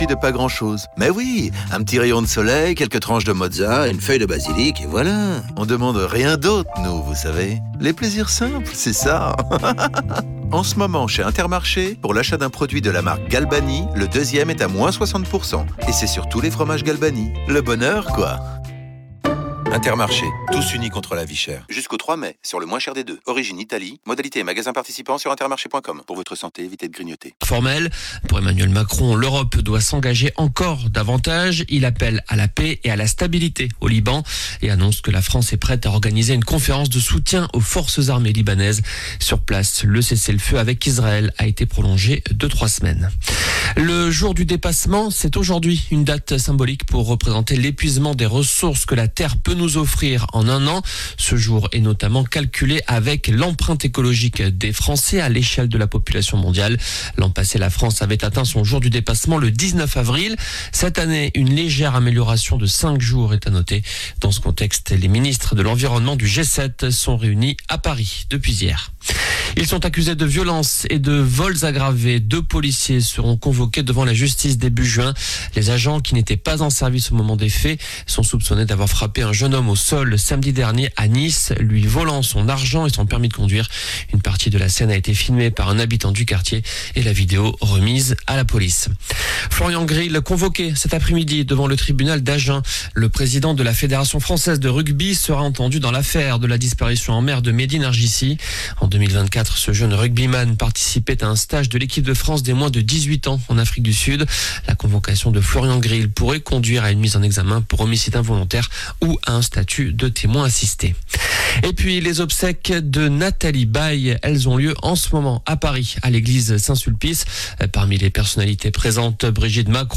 JOURNAL DU VENDREDI 24 AVRIL ( MIDi )